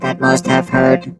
rick_kill_vo_06.wav